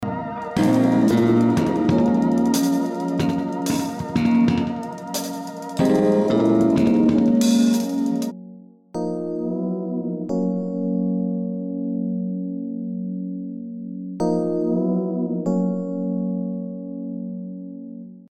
Ein F ist hier im A#Sus4 dabei.